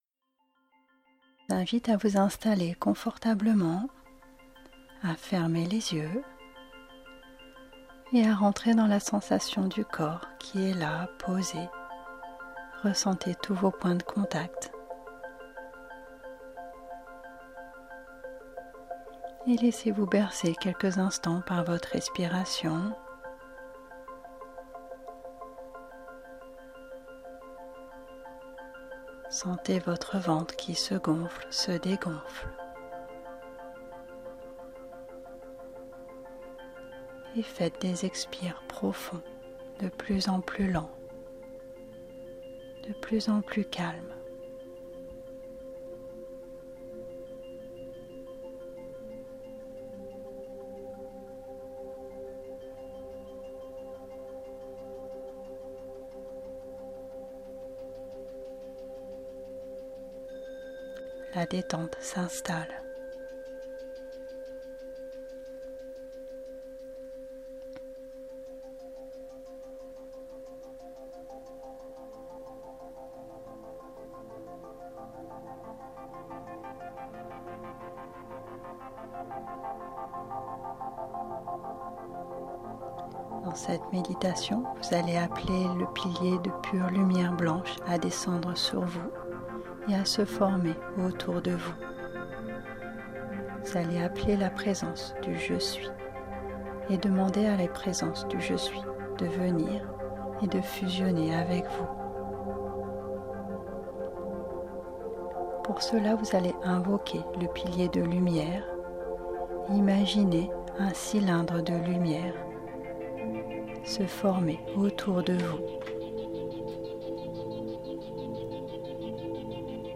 Cliquer ici pour télécharger l’audio : Fusion présence Je Suis AVEC musique
FusionPresenceJeSuis_MeditationGuideeMusique.mp3